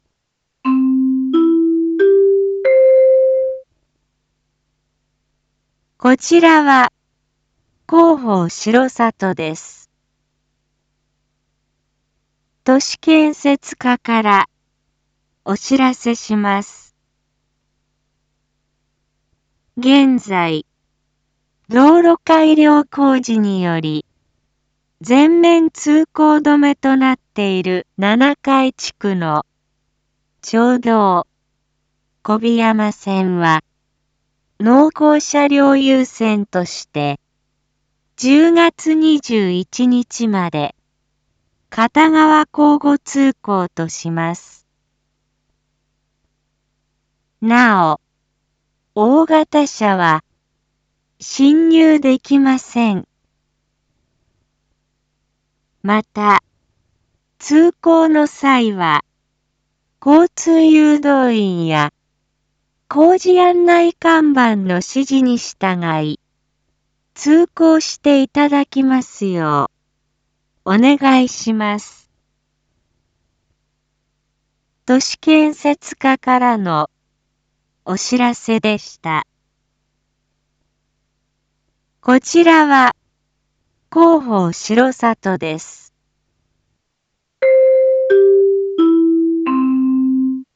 一般放送情報
Back Home 一般放送情報 音声放送 再生 一般放送情報 登録日時：2022-10-18 19:06:32 タイトル：町道４号線 交通規制について（七会地区限定） インフォメーション：こちらは広報しろさとです。